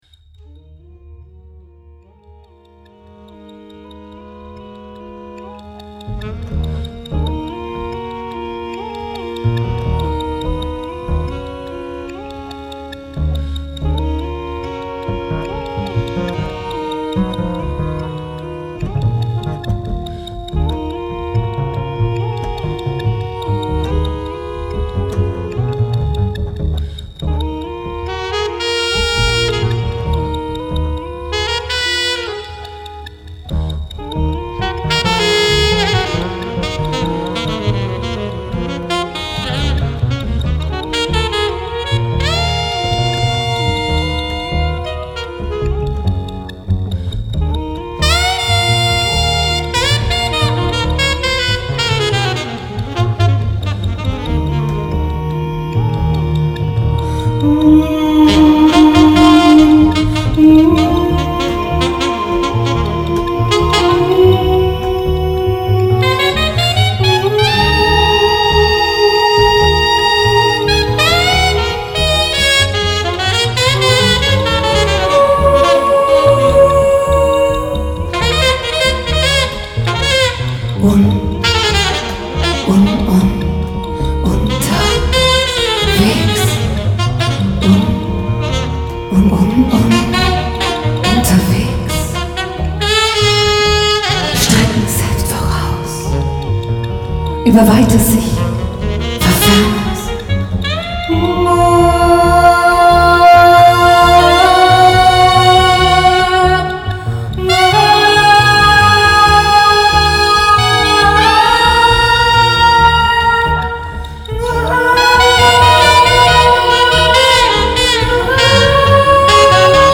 Style: from jazz to orchestral filmmusic